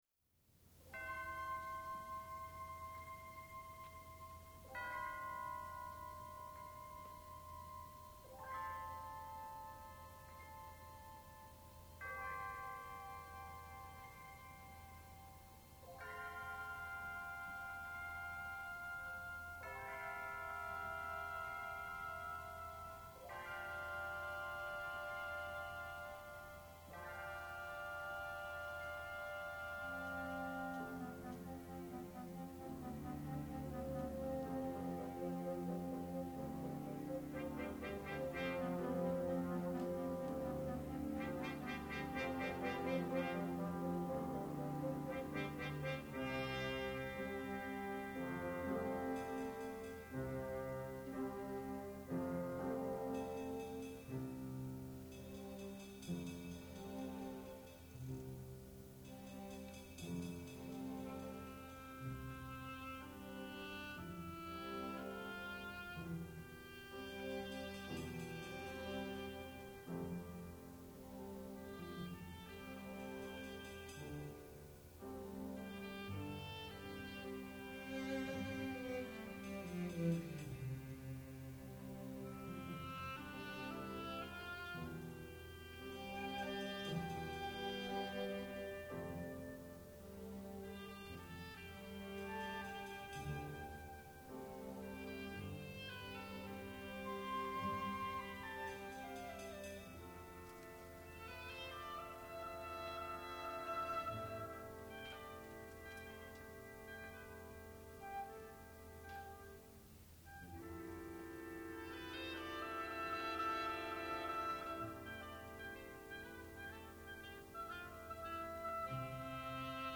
for Orchestra (1999)